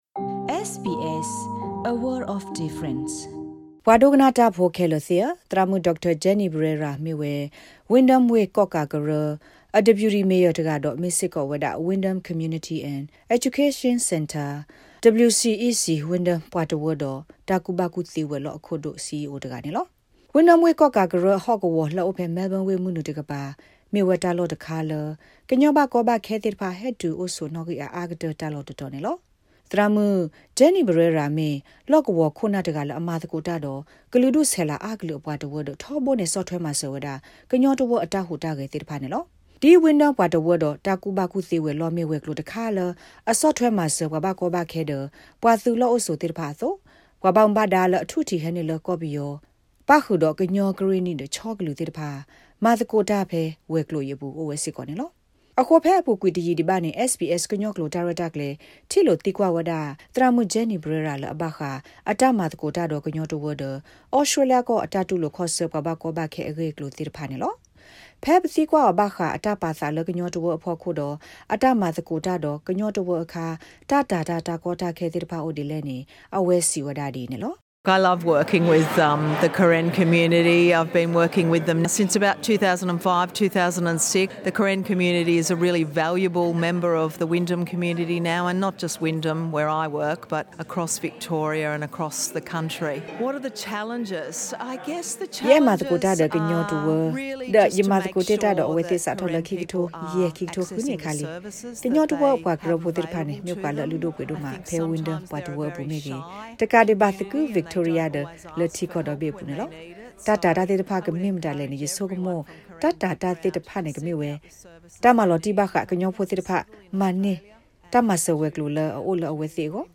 SBS Karen Program interview with Dr Jennie Barrera, CEO of Wyndham Community and Education Centre.